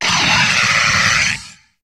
Cri de Bazoucan dans Pokémon HOME.